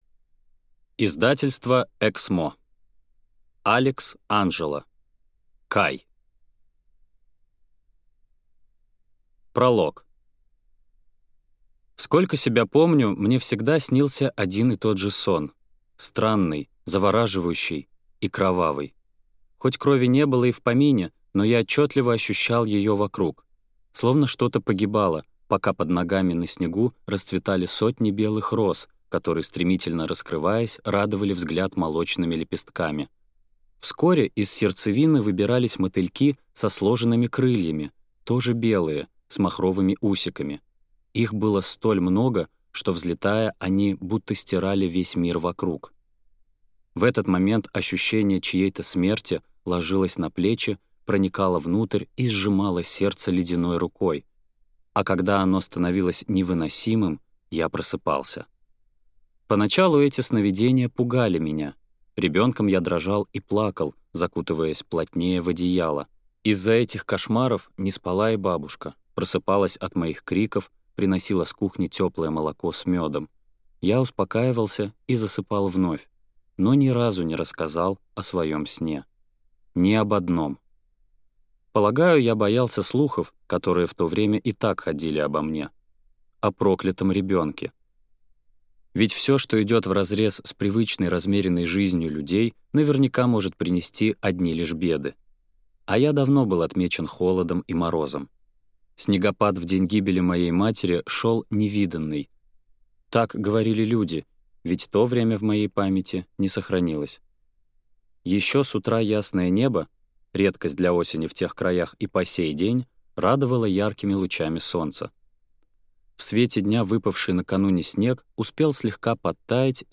Аудиокнига Кай | Библиотека аудиокниг